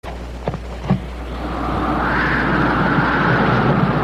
wind-blowing-whistling.ogg
Original creative-commons licensed sounds for DJ's and music producers, recorded with high quality studio microphones.
[wind-blowing-whistling]_sxn.mp3